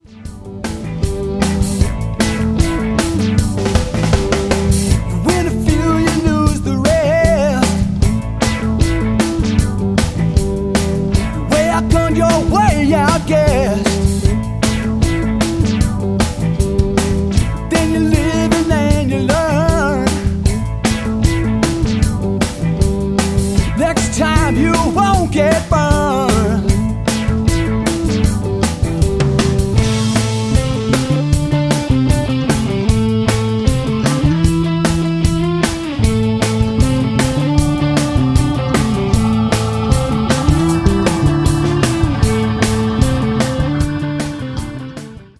old school rock, funk and R&B
vocals
guitar
keyboards